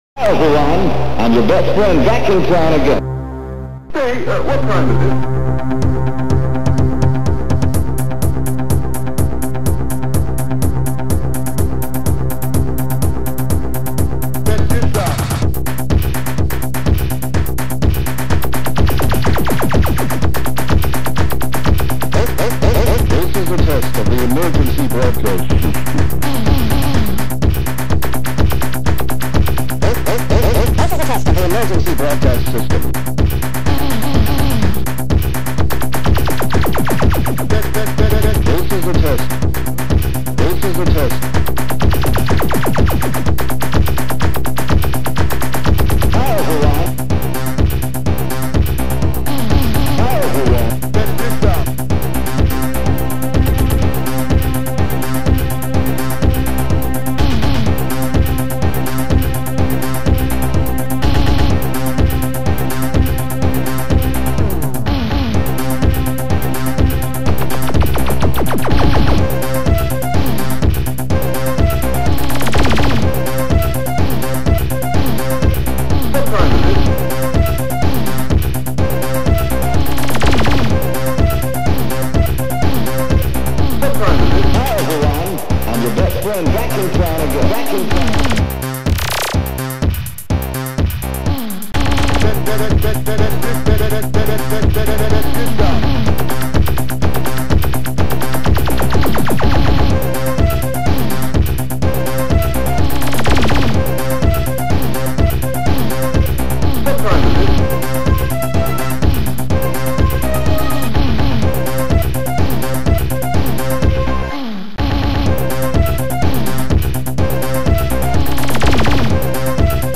Musique du g�n�rique